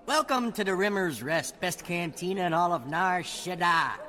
―The bouncer's welcome — (audio)
NarShaddBouncer_Welcome_to_the_Rimmers_Rest.ogg